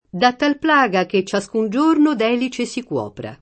elice [$li©e] s. f. (archit. «spirale»; med. «curva dell’orecchio») — antiq. come variante di elica — sim. il top. stor. E. (Gr.) e il pers. f. mit. E., che in passato indicò anche l’Orsa maggiore: da tal plaga Che ciascun giorno d’Elice si cuopra [
da ttal pl#ga ke ©©aSkun J1rno d $li©e Si kU0pra] (Dante)